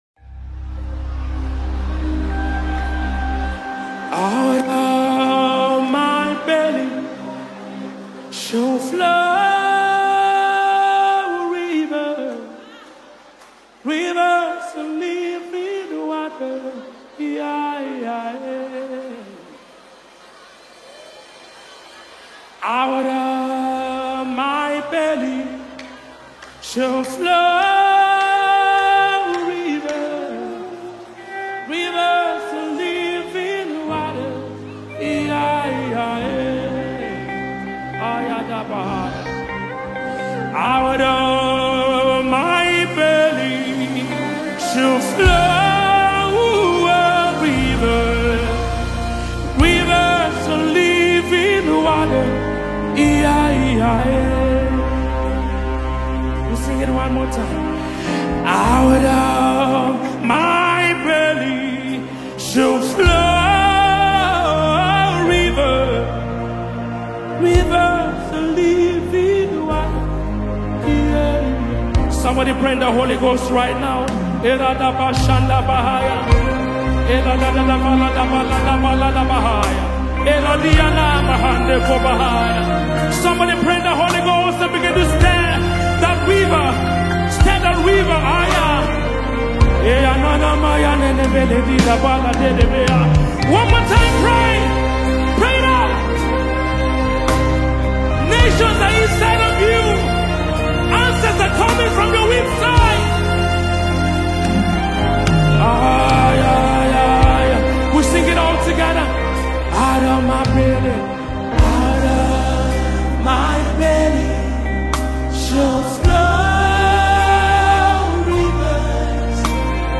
anthem sung both by infants and adults